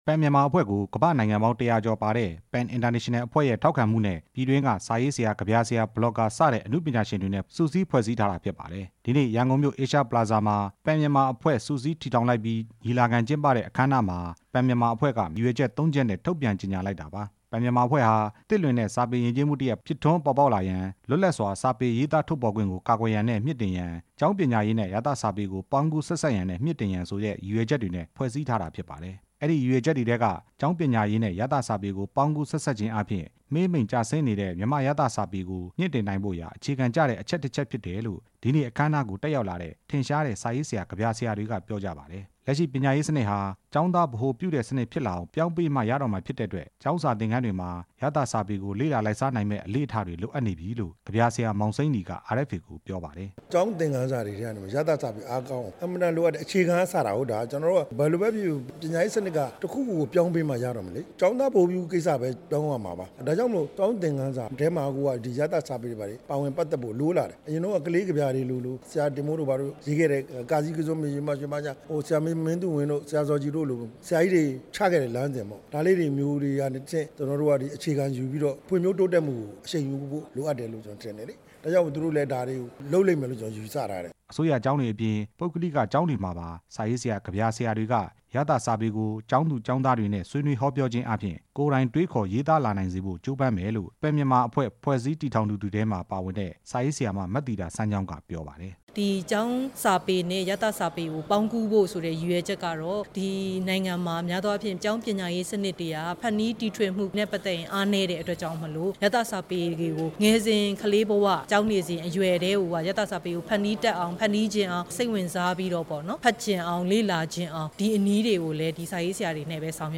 ရန်ကုန်မြို့ Asia Plaza မှာ ကျင်းပတဲ့ Pen Myanmar ညီလာခံအခမ်းအနားမှာ မြန်မာနိုင်ငံအတွင်း ရသစာပေ မှေးမှိန်ကျဆင်းနေတဲ့အတွက် ကျောင်း ပညာရေးနဲ့ ဆက်နွယ်ပြီး မြှင့်တင်ဖို့လုပ်ဆောင် မှာ ဖြစ်တယ်လို့ Pen Myanmar အဖွဲ့ဝင် စာရေးဆရာတွေက ပြောပါတယ်။